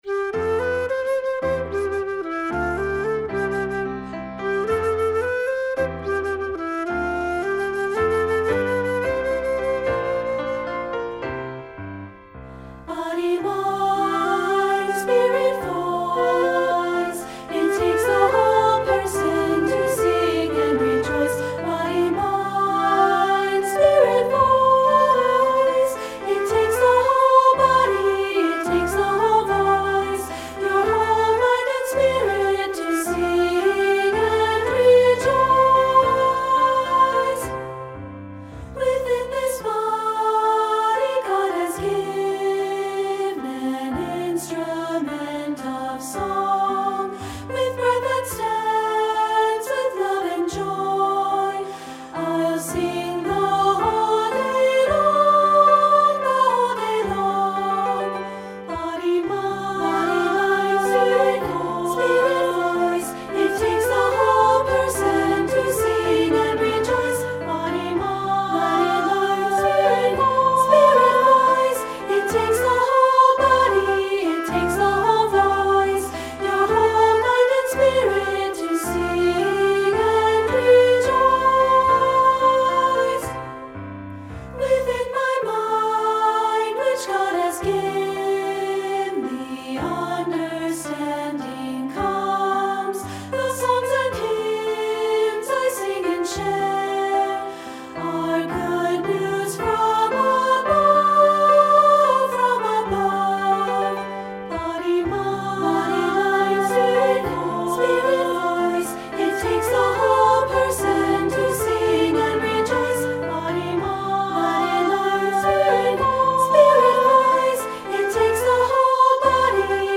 Unison/two-part with piano and optional C instrument